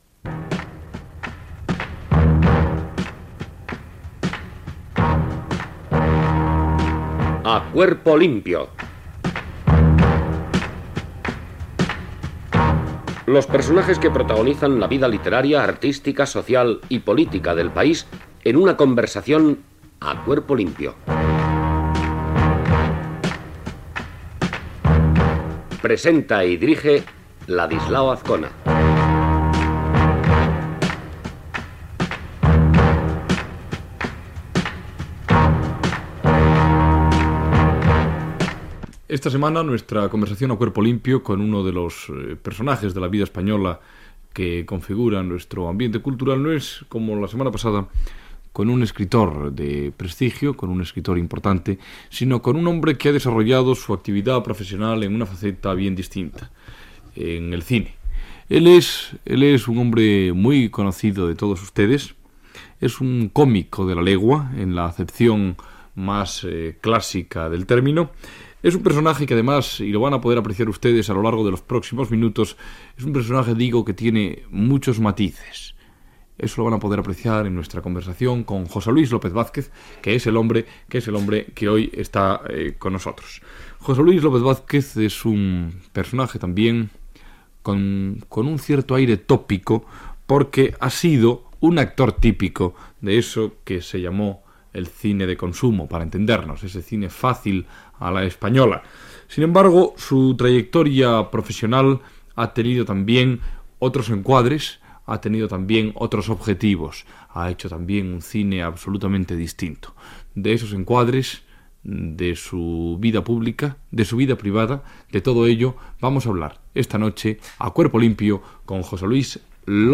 Careta del programa, presentació i entrevista a l'actor José Luis López Vázquez sobre la seva trajectòria professional